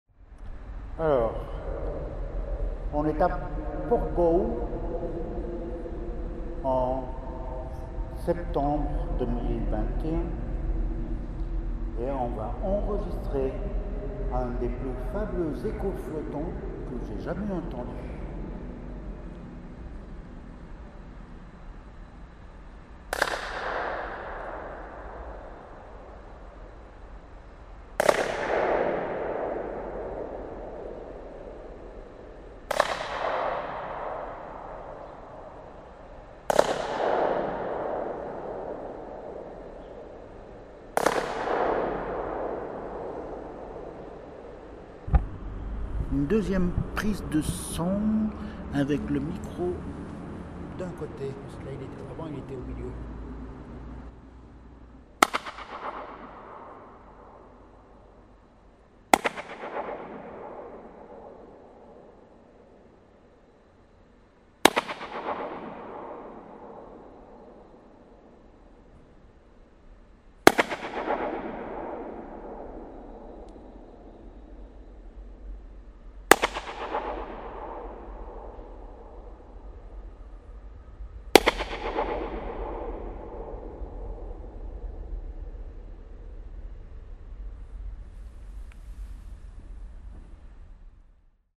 Si jusque-là les surfaces étaient relativement planes, on découvre que l’effet peut survenir également dans des espaces plus complexes, les variations fréquencielles apparaissant alors plus nettement :
Dans le tunnel qui passe sous la gare – Port-Bou, Catalunya
FX_echo_flottant_portBOU_tunnel.mp3